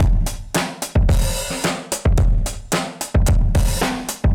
Index of /musicradar/dusty-funk-samples/Beats/110bpm/Alt Sound
DF_BeatB[dustier]_110-02.wav